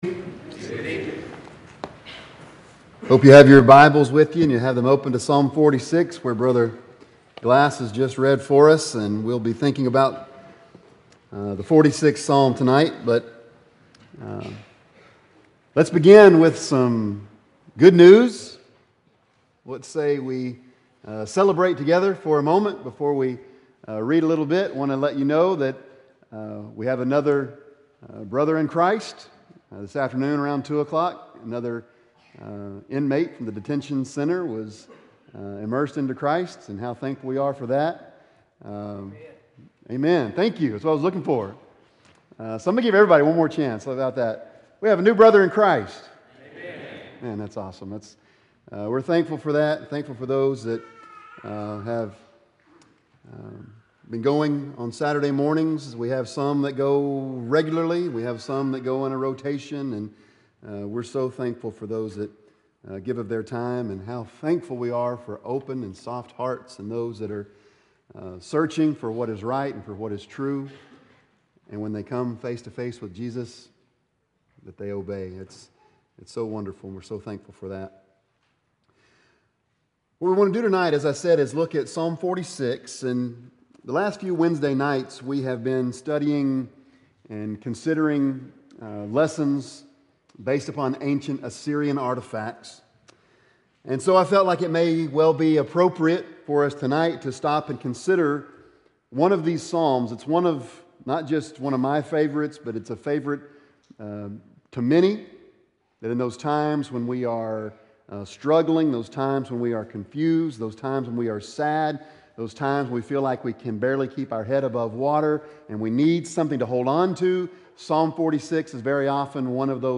God: Our Fortress – South Green Street church of Christ